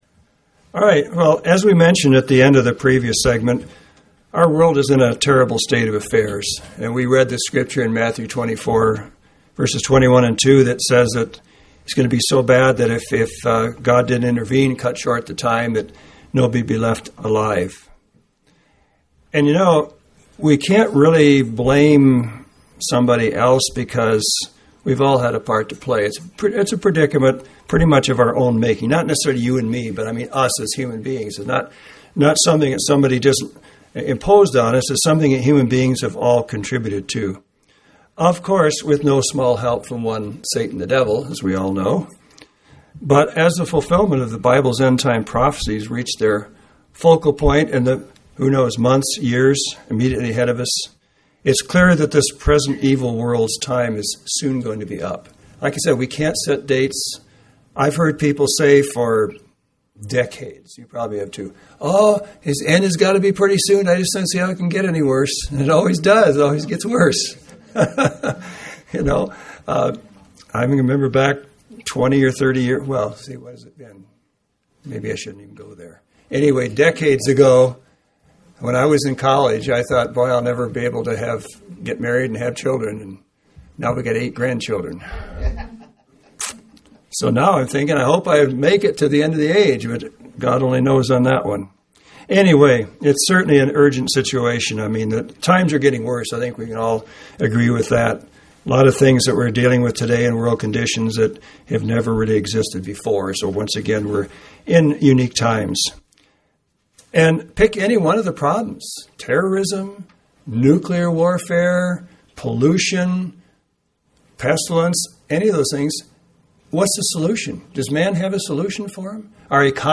UCG Sermon Notes WHY THE WORLD NEEDS GOD’S KINGDOM—NOW!